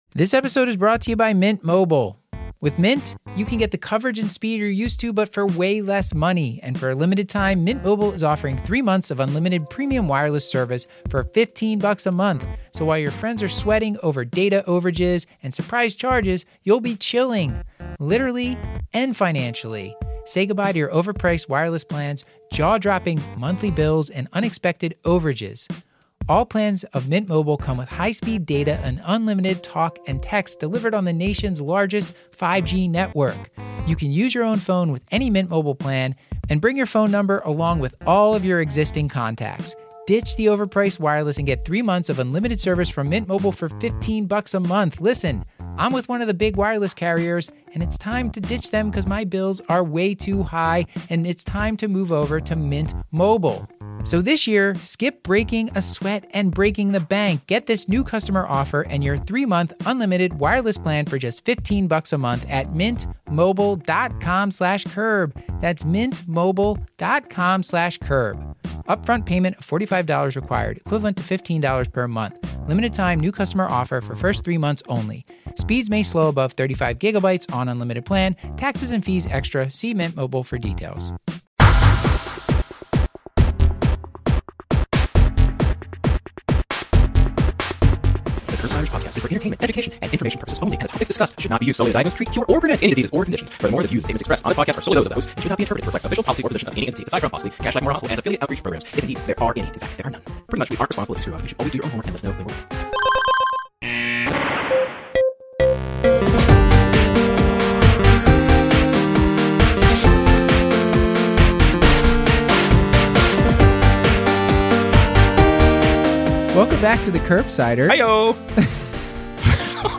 Our final live recap show from ACP 2018 covering: human microbiome, copper deficiency, POTS syndrome, substances of abuse, drugs for delirium and how to be more like Leonardo Da Vinci.